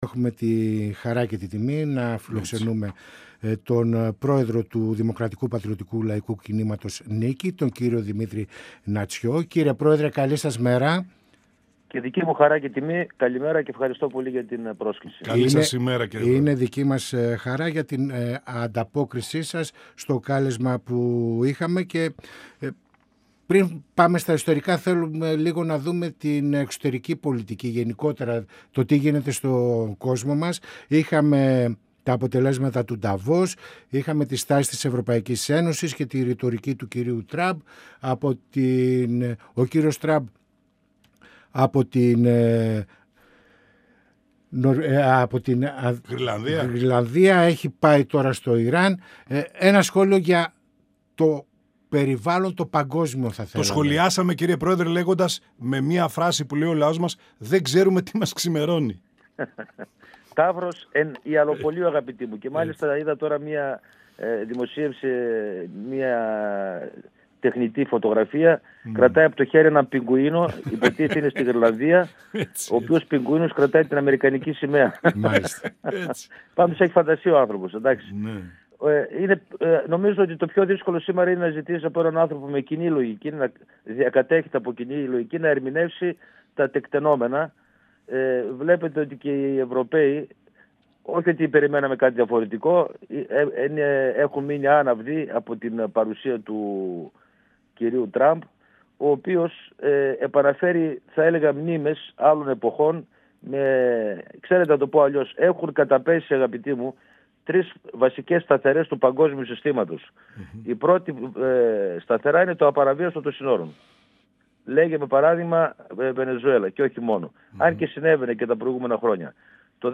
Στις πολιτικές εξελίξεις, στα εθνικά θέματα, το σκάνδαλο του ΟΠΕΚΕΠΕ, αλλά και τα προβλήματα της καθημερινότητας, όπως το πρόβλημα της ακρίβειας σε συνδυασμό με την αγοραστική δύναμη των πολιτών αναφέρθηκε ο Πρόεδρος της «ΝΙΚΗΣ» Δημήτρης Νατσιός, μιλώντας στην εκπομπή «Πανόραμα Επικαιρότητας» του 102FM της ΕΡΤ3.
Συνεντεύξεις